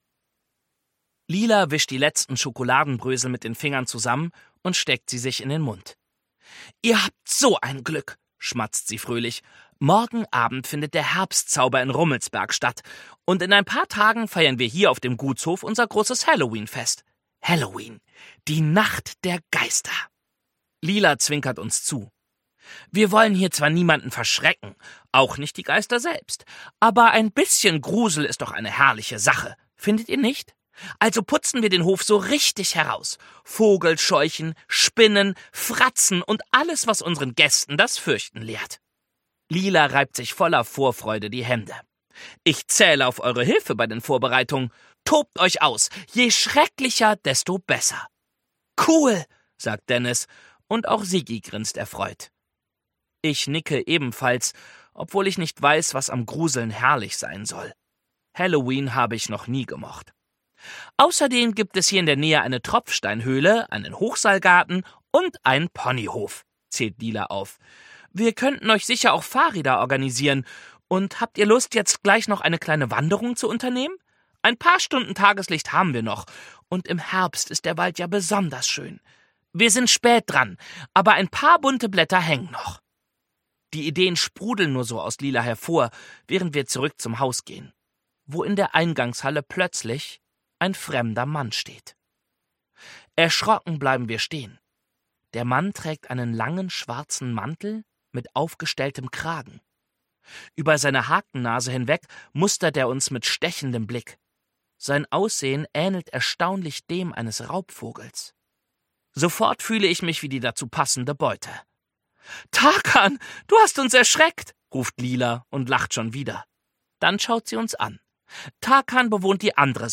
Ungekürzte Lesung